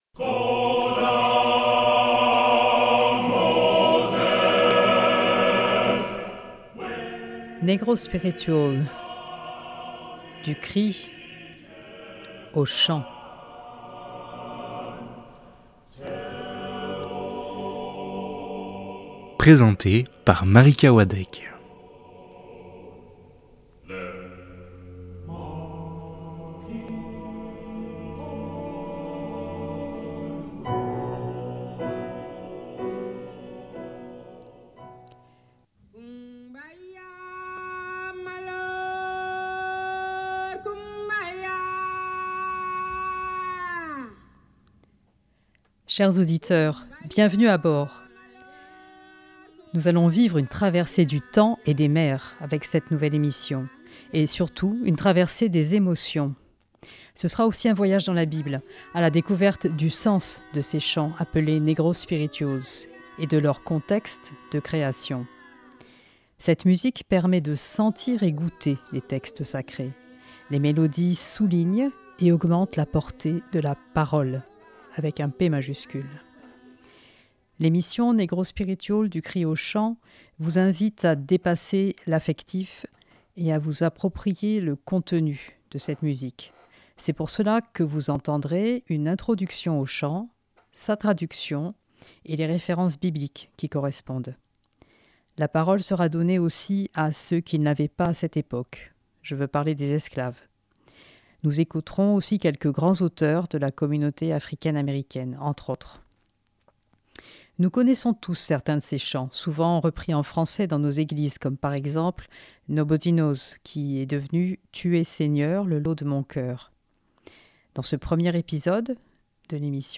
Les récits de grands auteurs et surtout les témoignages d’anciens esclaves dialoguent avec les interprétations authentiques des negro-spirituals.